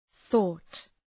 Προφορά
{ɵɔ:t}